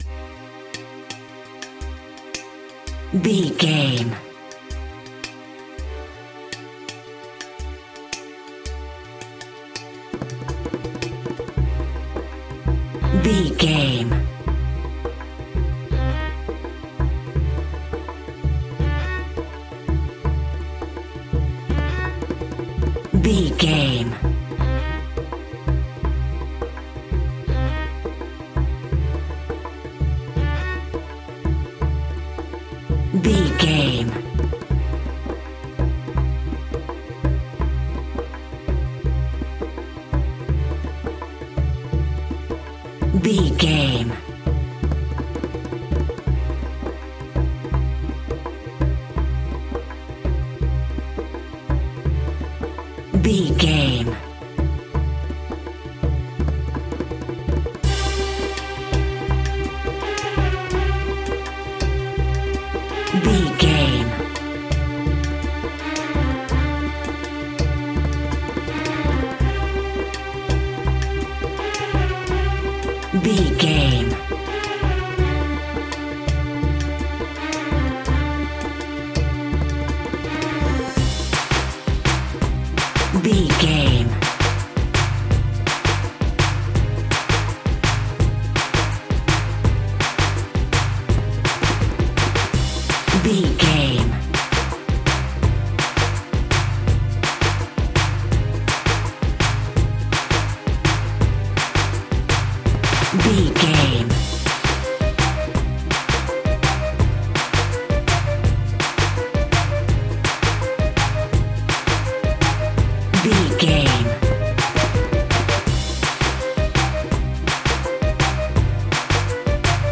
Aeolian/Minor
Fast
World Music
percussion